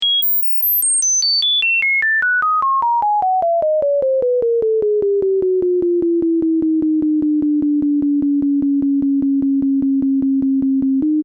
A different non-visual way of experiencing a graph might be as a sound, where the pitch relates to the ‘y-value’, while the ‘x-value’ operates as time.
(Zero is taken as middle C and an increase of 1 unit corresponds to a semitone musically.)